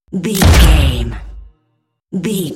Dramatic drum hit electricity
Sound Effects
Atonal
heavy
intense
dark
aggressive
hits